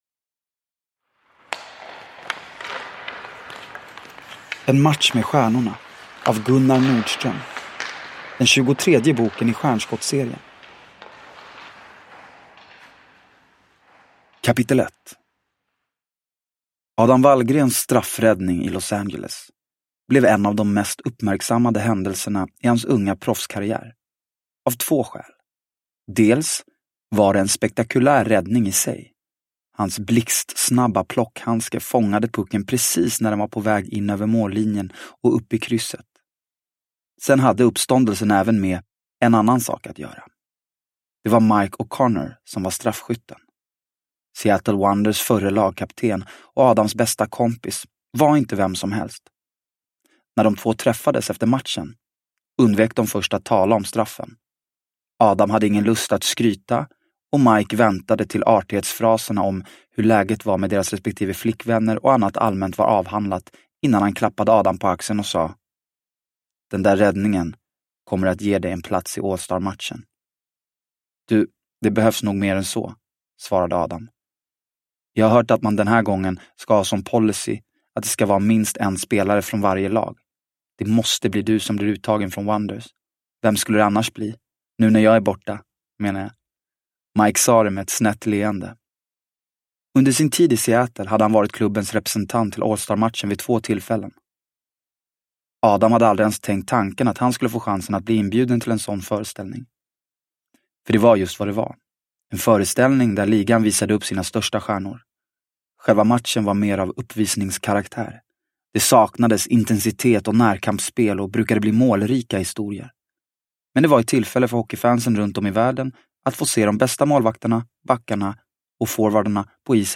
En match med stjärnorna – Ljudbok – Laddas ner